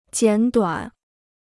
简短 (jiǎn duǎn) Free Chinese Dictionary